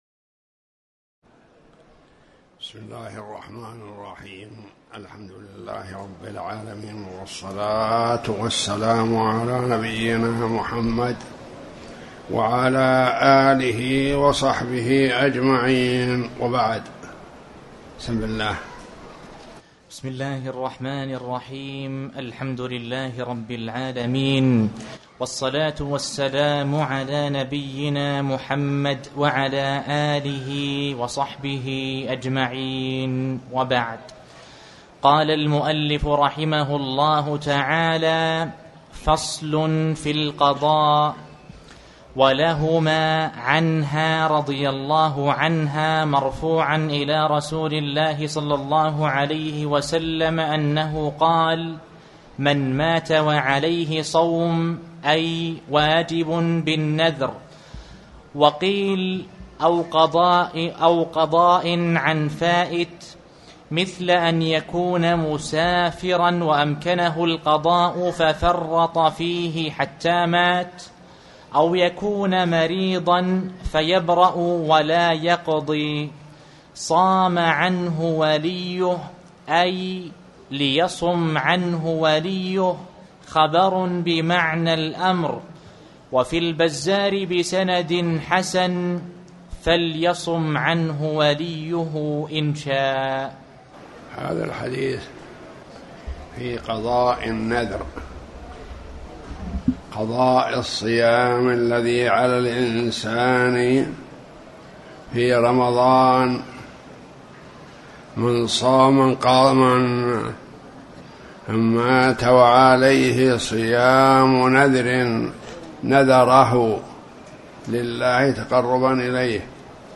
تاريخ النشر ١ ذو القعدة ١٤٣٩ هـ المكان: المسجد الحرام الشيخ